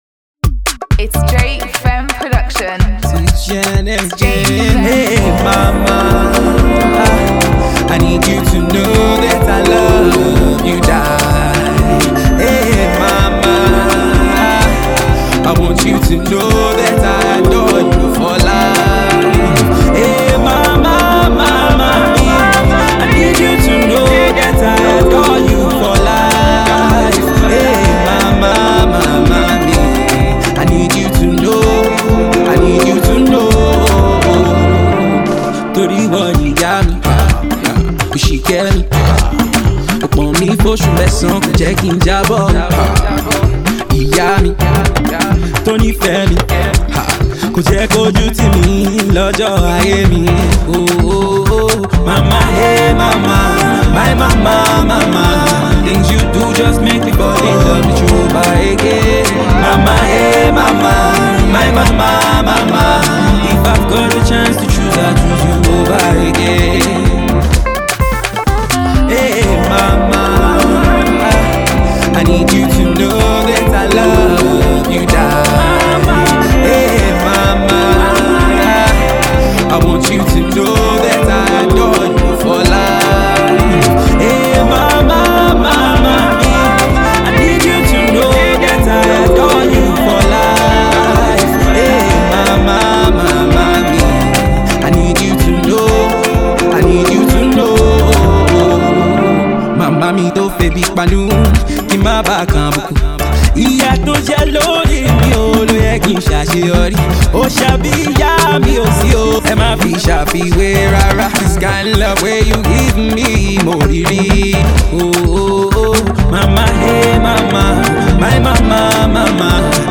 Pop
traditional talking drums
guitarist